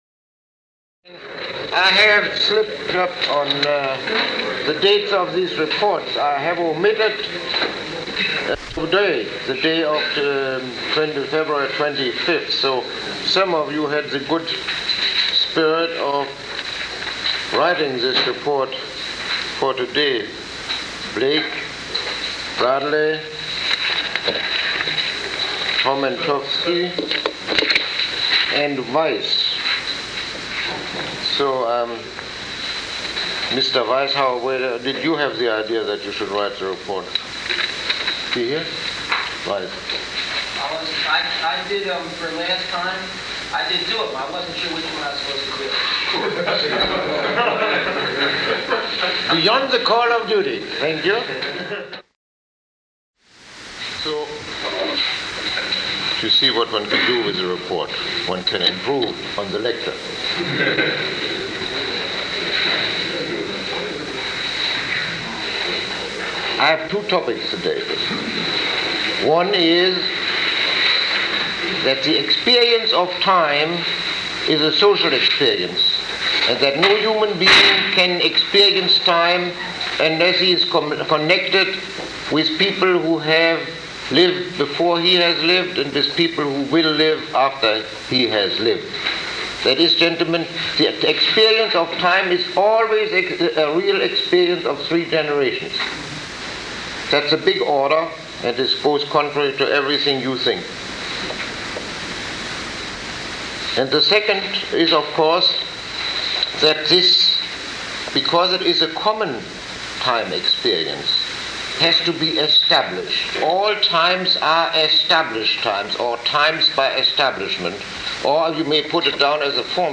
Lecture 07